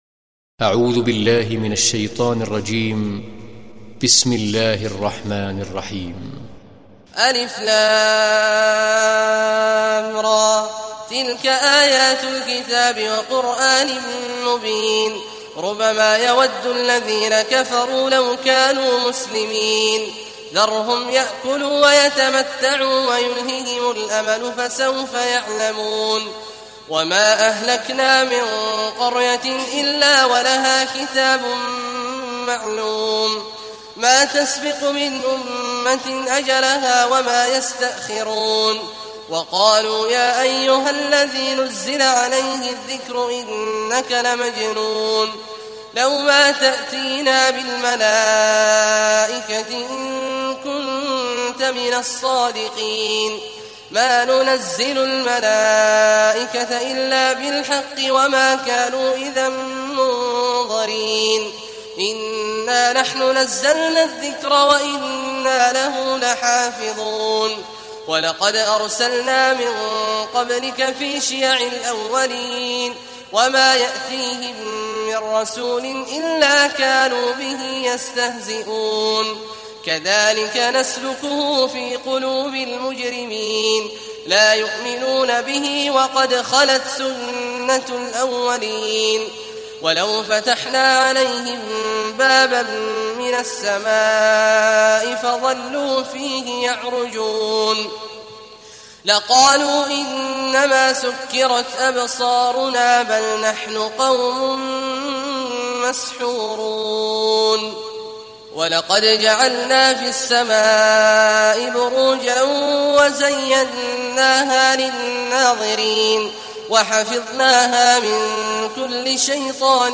دانلود سوره الحجر mp3 عبد الله عواد الجهني روایت حفص از عاصم, قرآن را دانلود کنید و گوش کن mp3 ، لینک مستقیم کامل